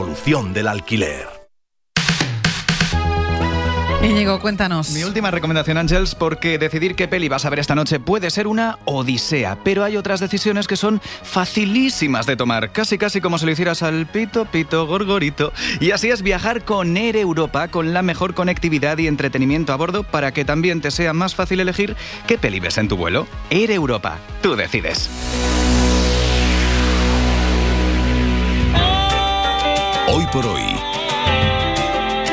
Publicidad en directo en la radio: campaña de Air Europa para la Cadena SER en 'Hoy por Hoy'